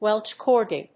アクセントWélsh córgi